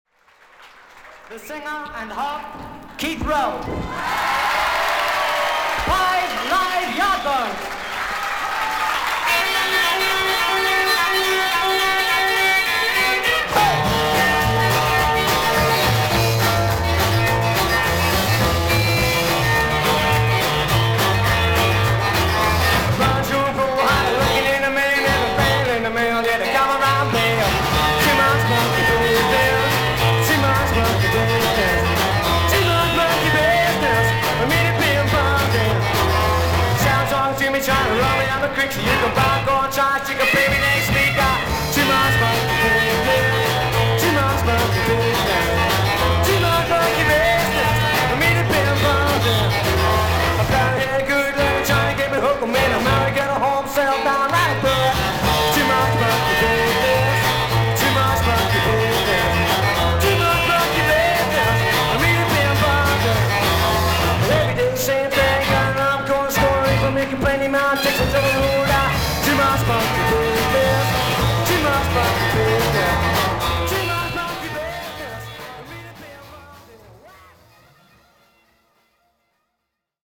音のグレードはVG++〜VG+:少々軽いパチノイズの箇所あり。少々サーフィス・ノイズあり。クリアな音です。